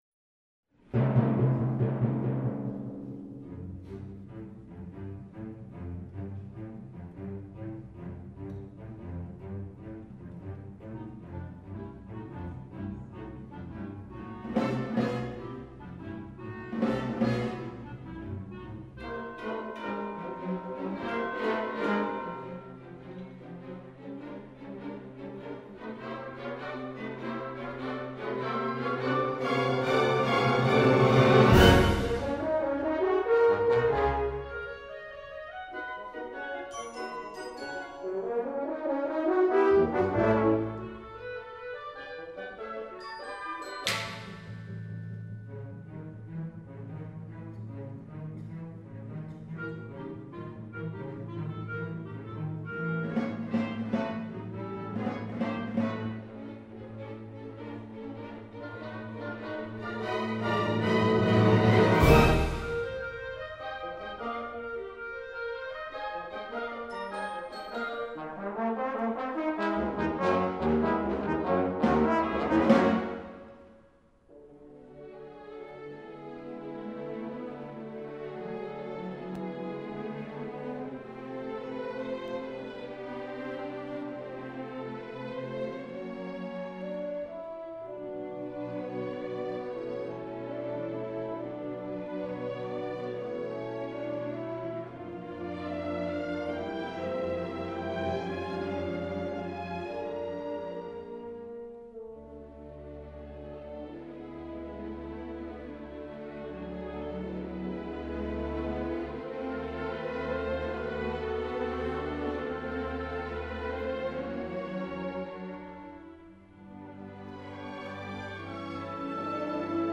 for large orchestra